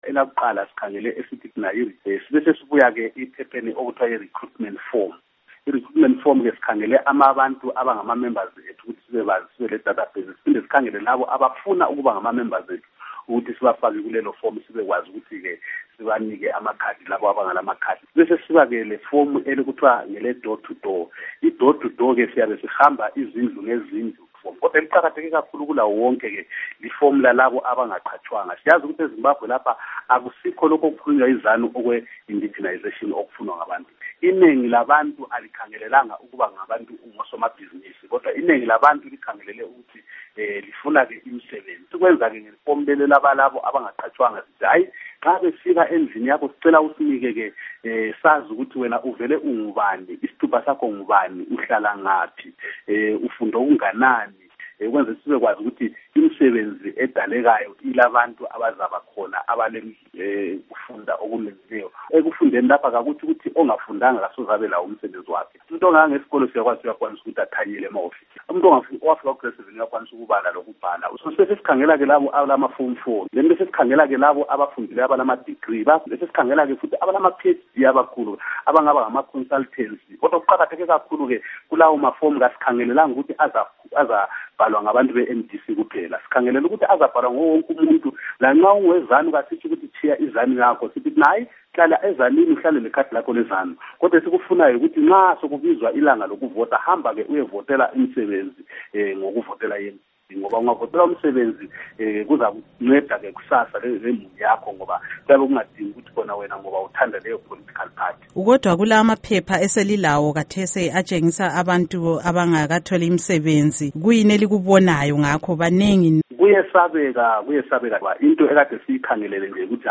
Ingxoxo LoMnu.